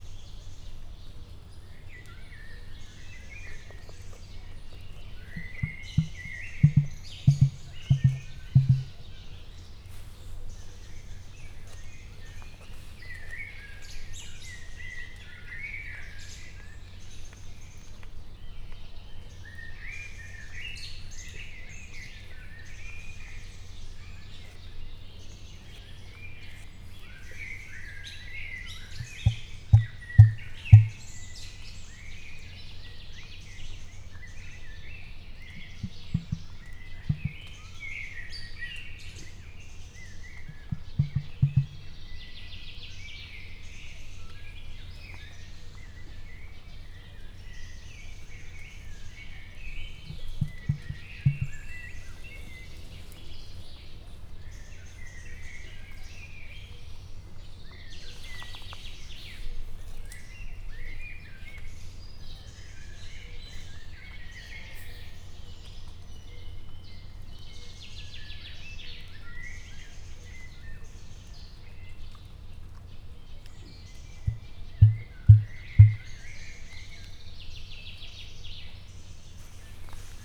Directory Listing of /_MP3/allathangok/miskolcizoo2018_professzionalis/emu/
visszafogottan_kornyezethangja_miskolczoo0120.WAV